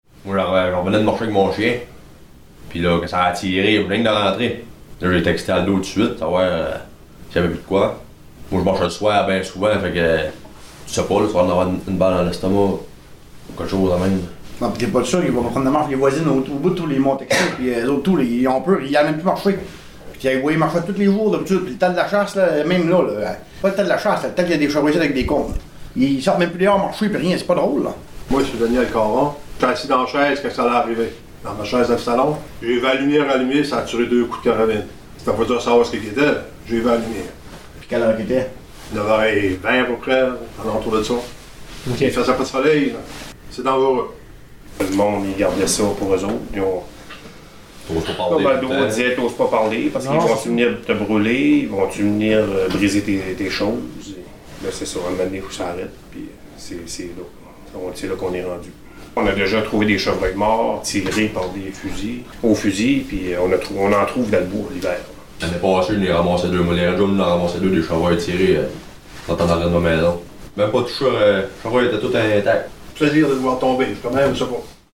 Extraits-des-discussions-CORPS-DU-TEXTE.mp3